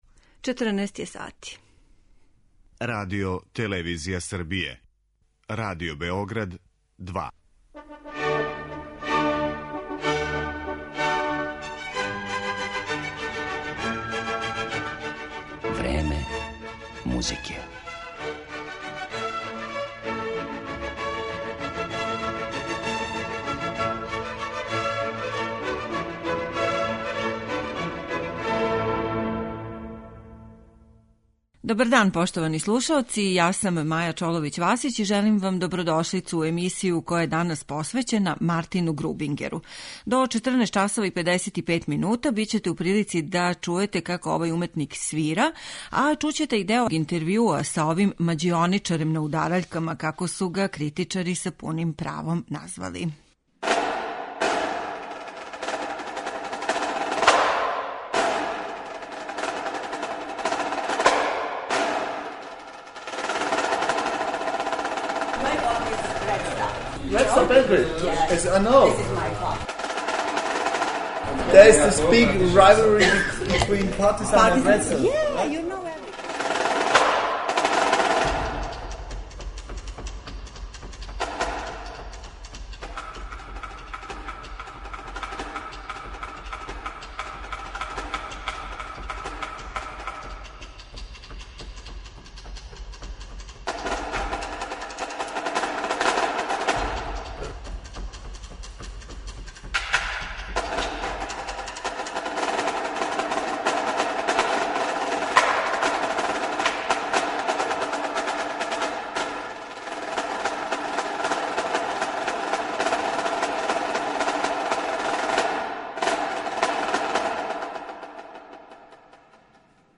'Мађионичар на удараљкама', надимак је који са пуним правом носи изузетни аустријски перкусиониста Мартин Грубингер, један од најзаслужнијих уметника који је промовисао удараљке у солистички инструмент.